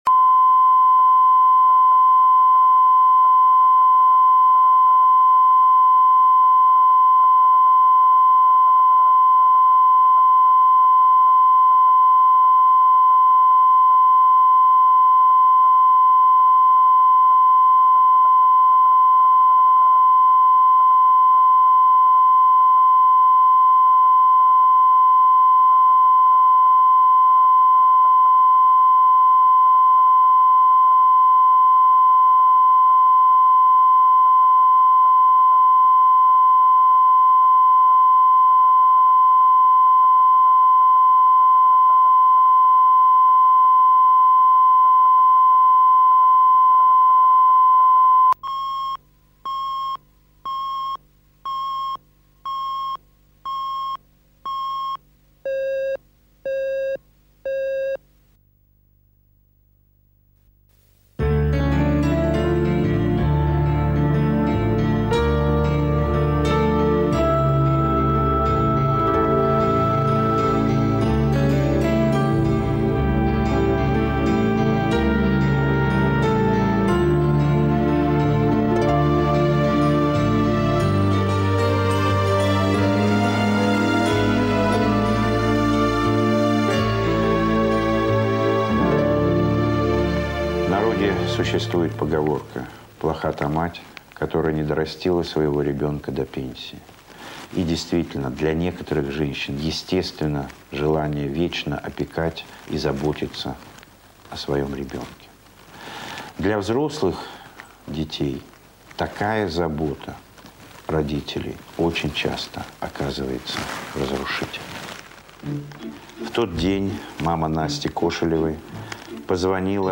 Аудиокнига Требуется высокий блондин | Библиотека аудиокниг